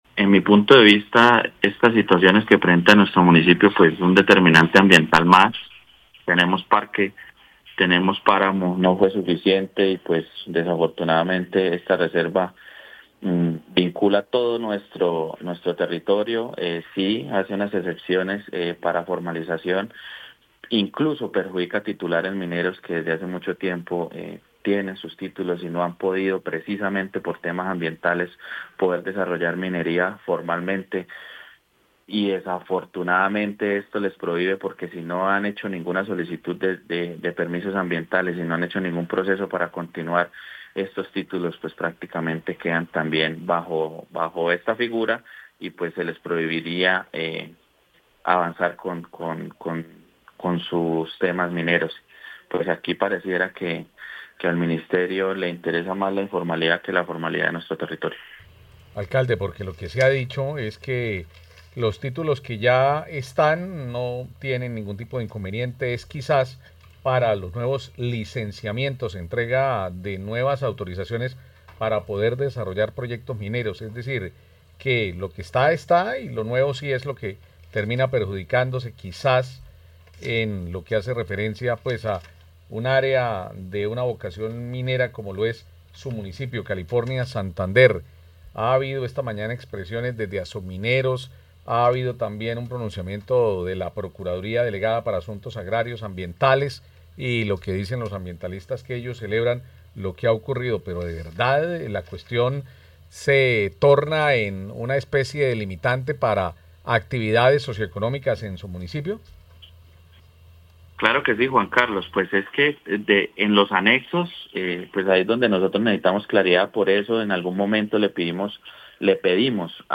Danilo Valbuena Pabón, alcalde de California
En diálogo con Caracol Radio el alcalde de California en Santander, Danilo Valbuena Pabón, se despachó contra el Ministerio de Ambiente por el proyecto de resolución para declarar Zona de Reserva Temporal en Santurbán.